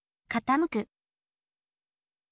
katamuku